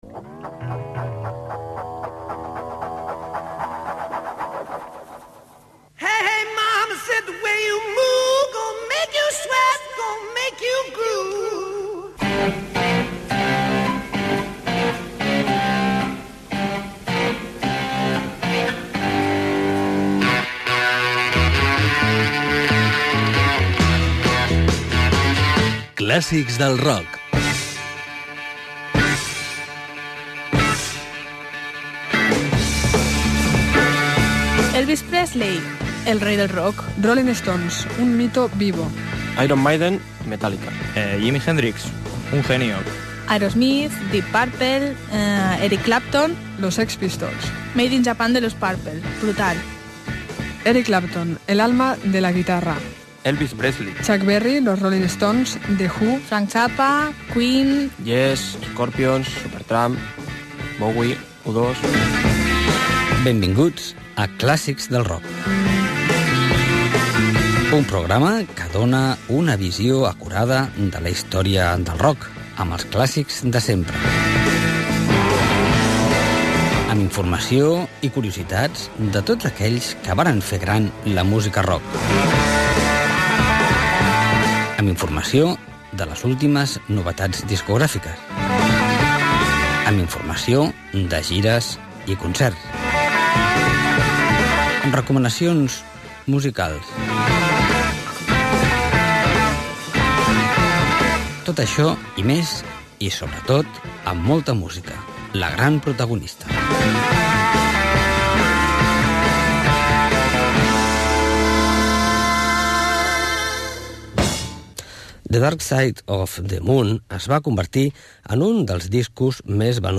Gènere radiofònic Musical Data emissió 2012-05-26 Banda FM Localitat Hospitalet de Llobregat